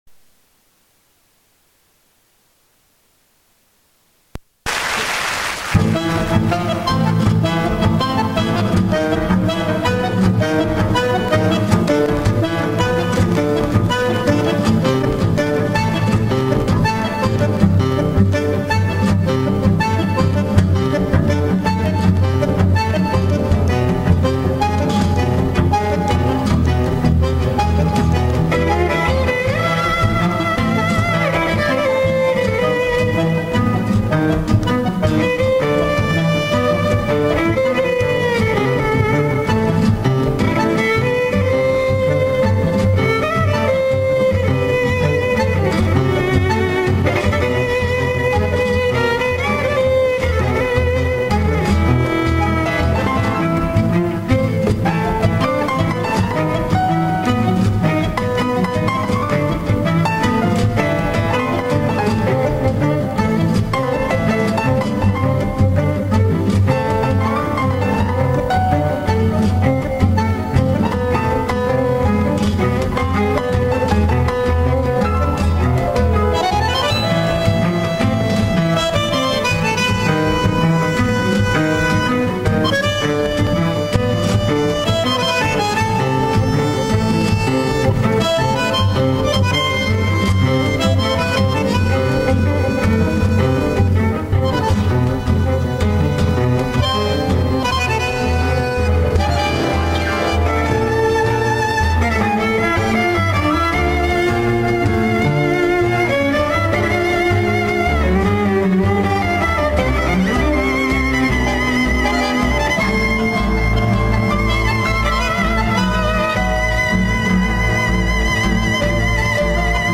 Ｔａｎｇｏ20選-1(ｱﾙｾﾞﾝﾁﾝ･ﾀﾝｺﾞ)
violin
ﾊﾞﾝﾄﾞﾈｵﾝ